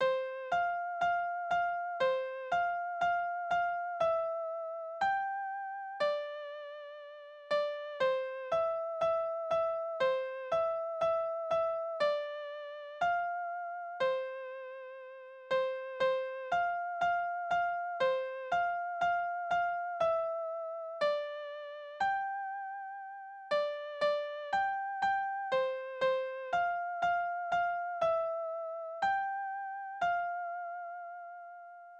Kinderlieder
Tonart: C-Dur
Taktart: 2/4
Tonumfang: Quinte
Besetzung: vokal